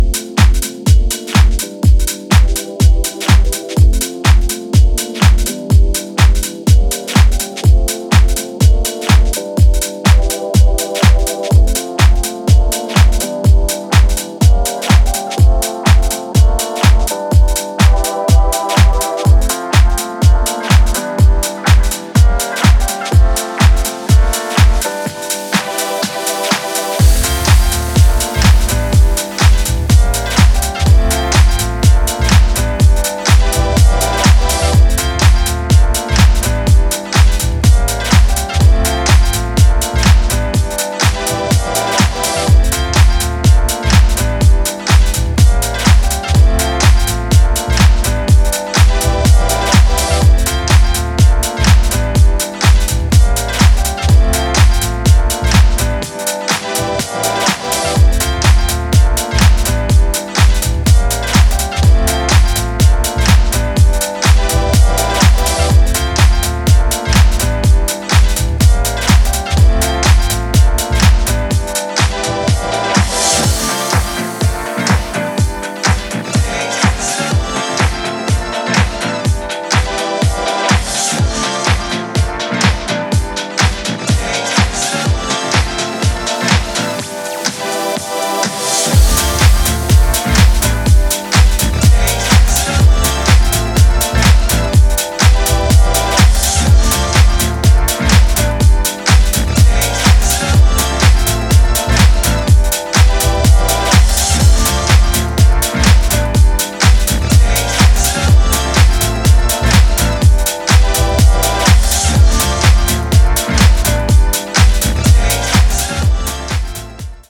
定番的な楽曲からレアブギーまでをDJユースに捌いた